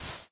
sand4.ogg